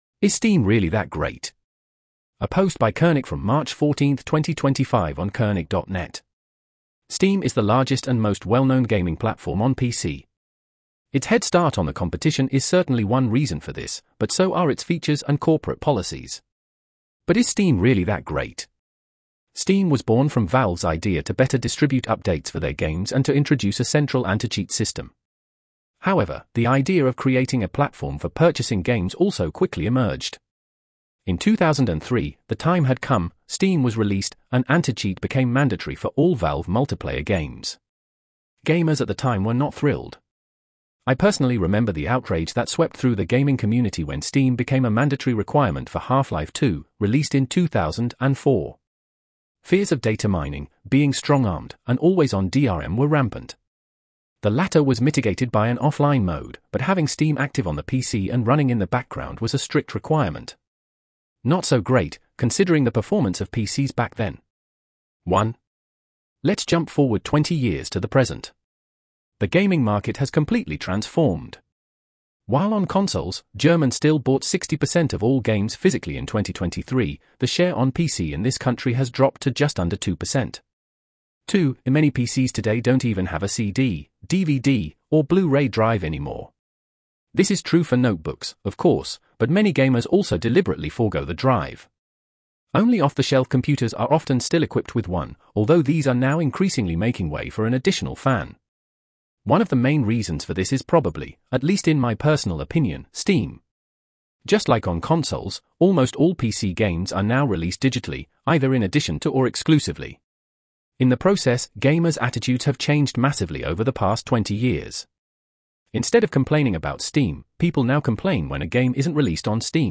🔊 Read out blogpost